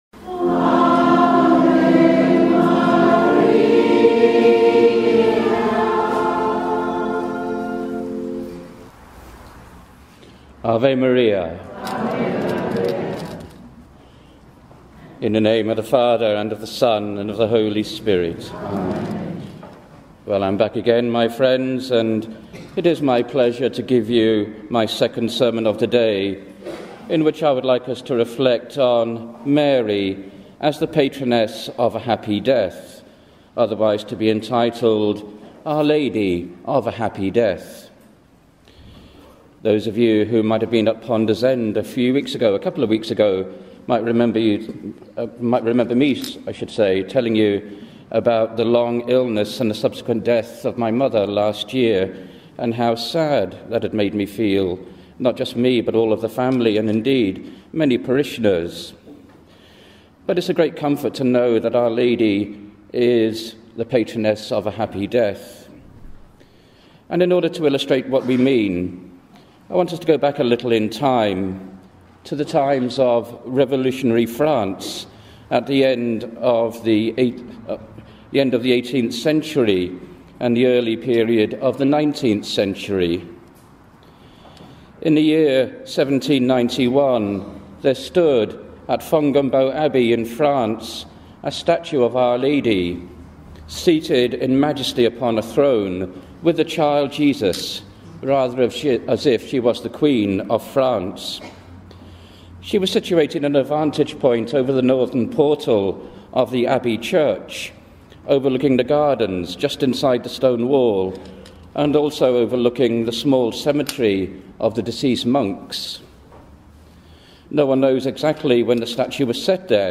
Sermon
St. Terese Catholic Church, Newbury Park, London.